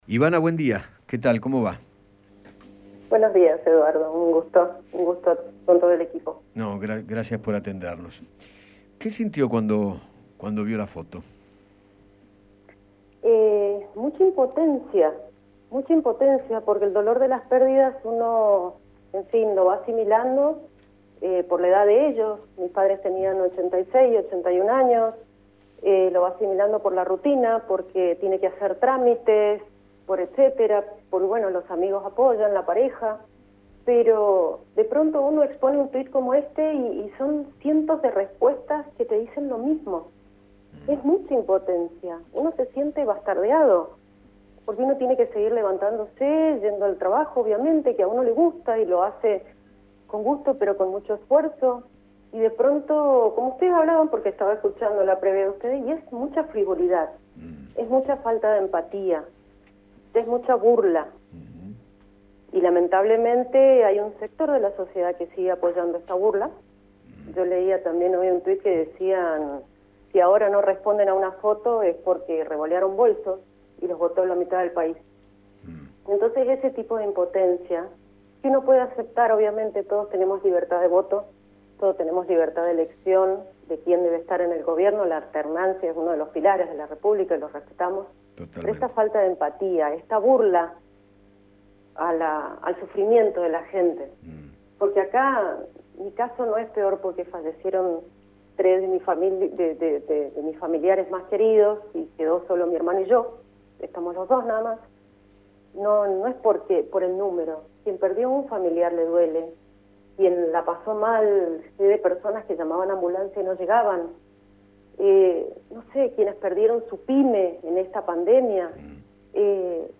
empresario gastronómico